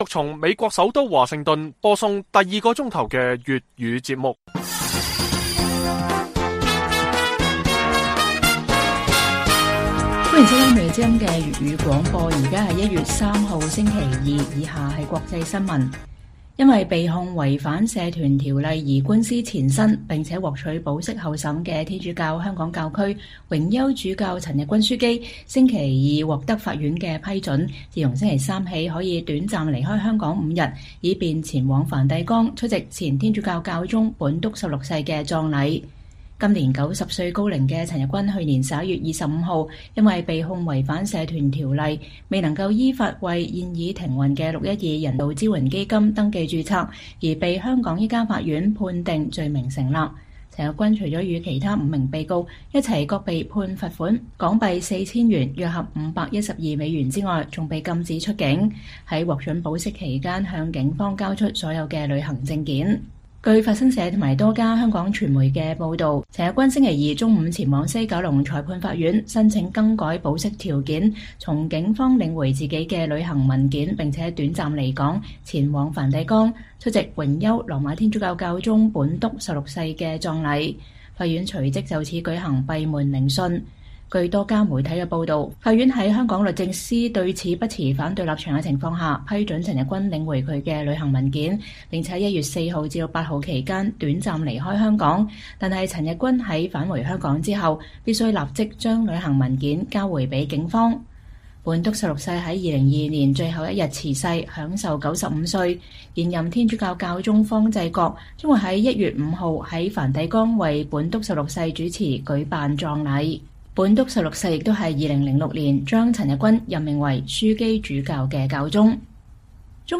粵語新聞 晚上10-11點: 香港榮休主教陳日君樞機獲准離港出席本篤十六世葬禮